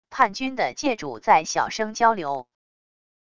叛军的界主在小声交流wav音频